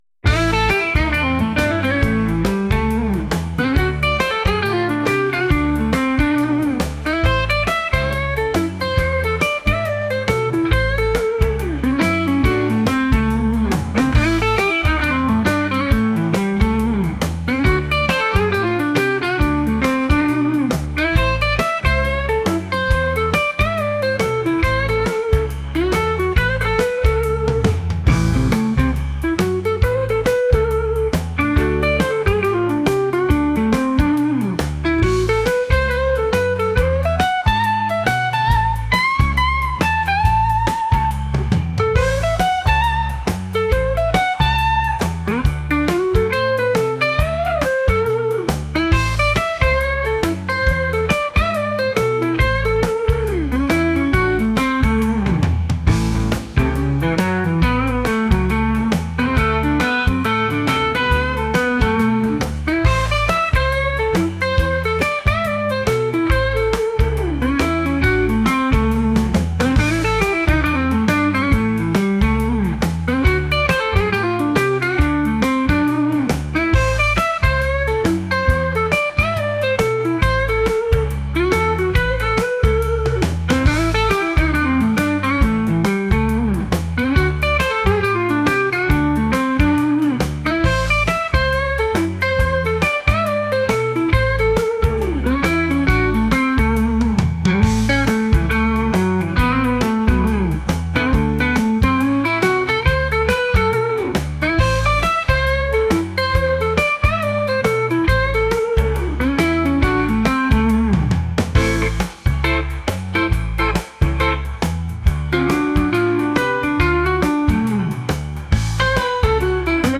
rock | blues | retro